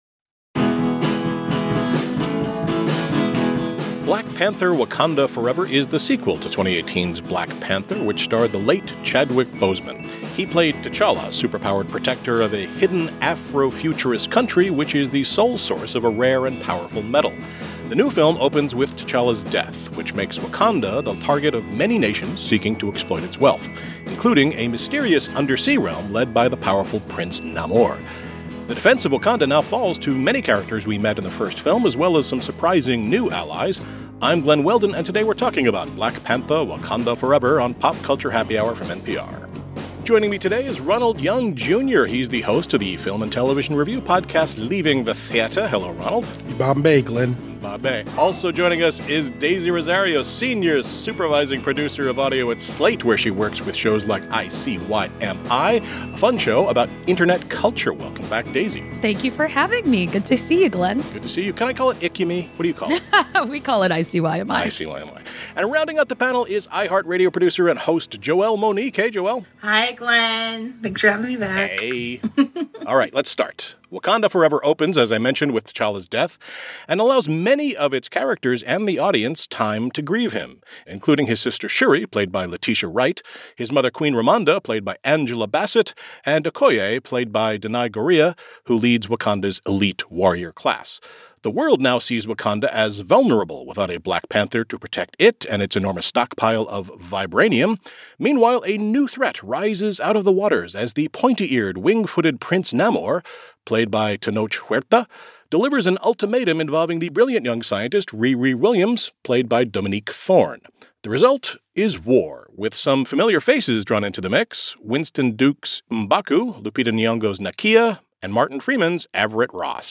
Movie Review